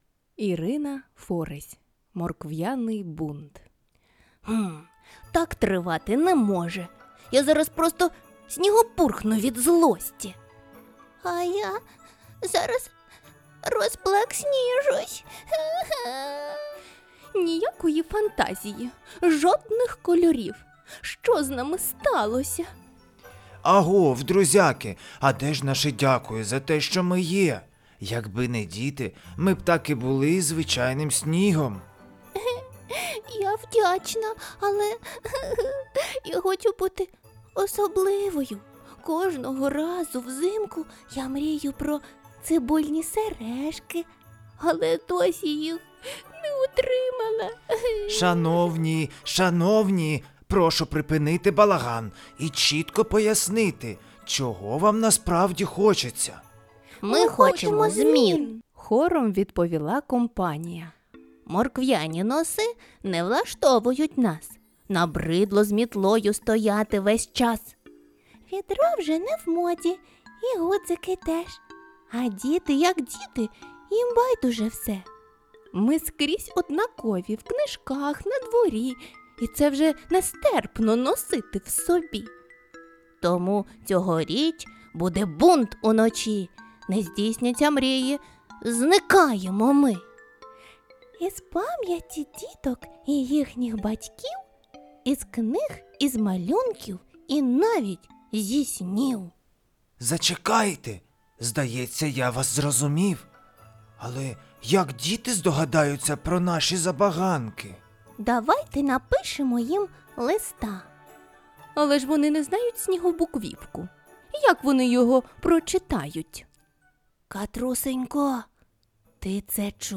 Аудіоказка Морквяний бунт